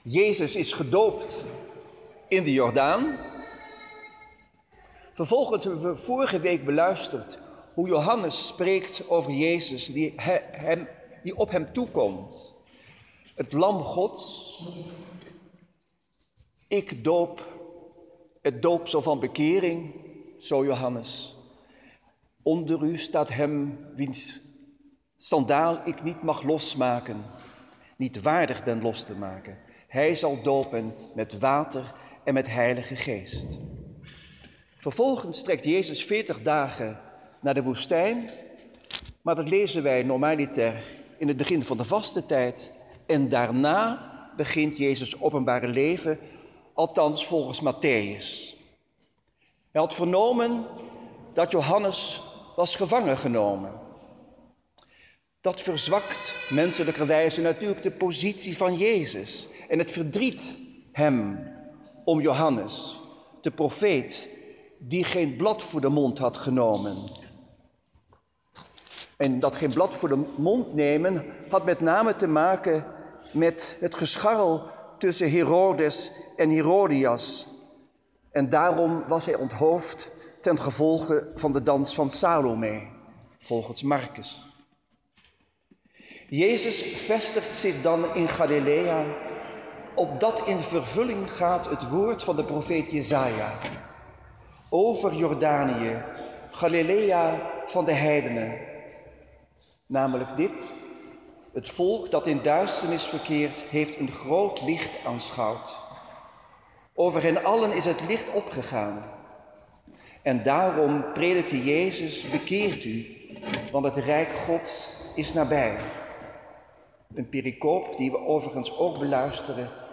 Preek-1.m4a